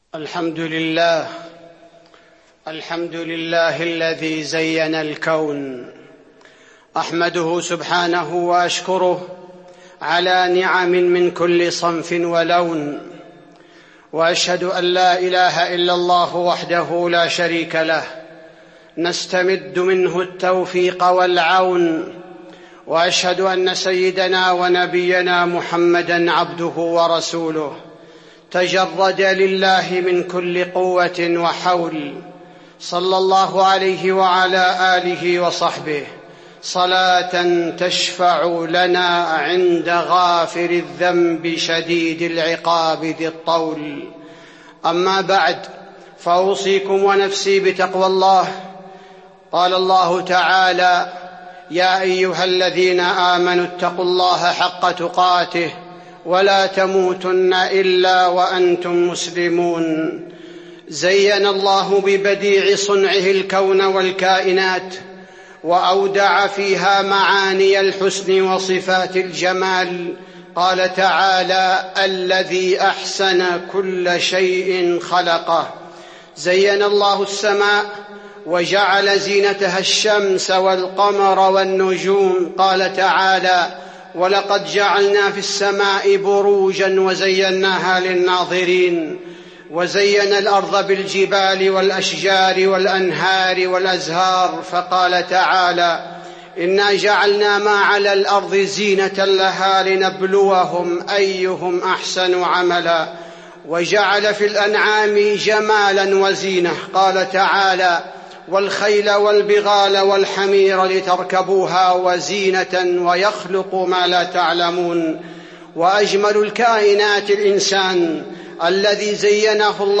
تاريخ النشر ٨ ذو القعدة ١٤٤٢ هـ المكان: المسجد النبوي الشيخ: فضيلة الشيخ عبدالباري الثبيتي فضيلة الشيخ عبدالباري الثبيتي آداب الزينة في الإسلام